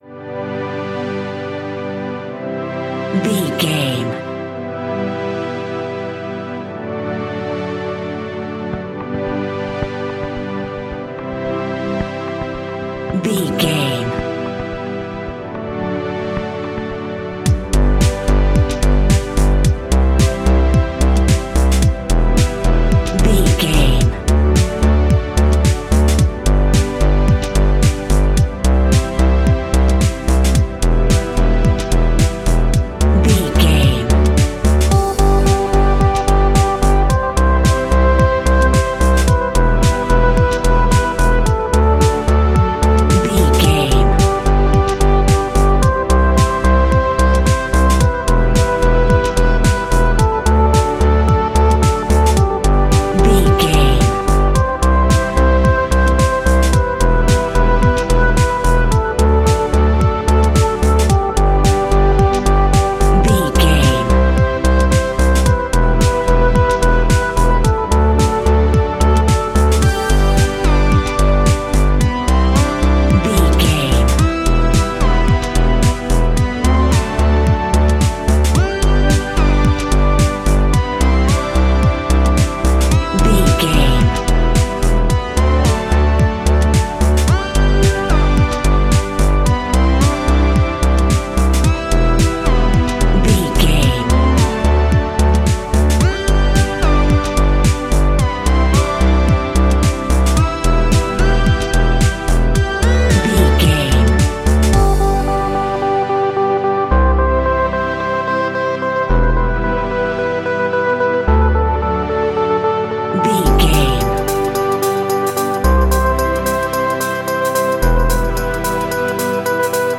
Melodic Electronica.
In-crescendo
Ionian/Major
groovy
uplifting
futuristic
driving
energetic
strings
synthesiser
drum machine
electronic
synth leads
synth bass